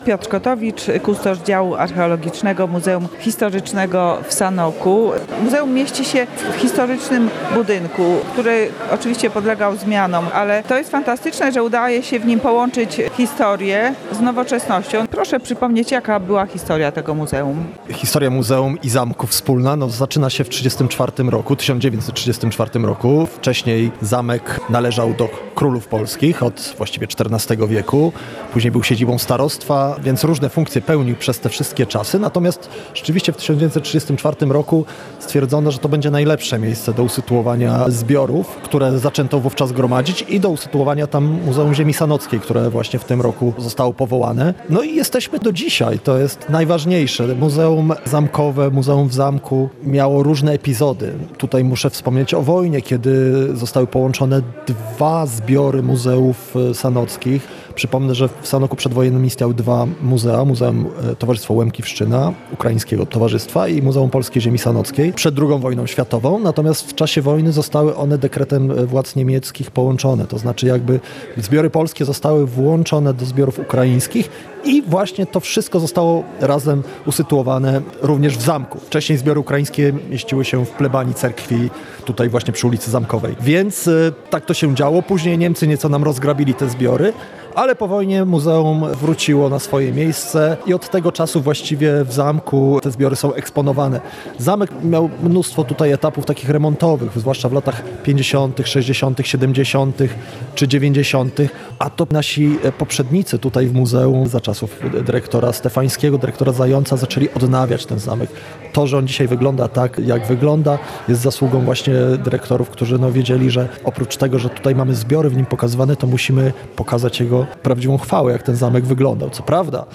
Rozmowa
z uczestnikami gali jubileuszowej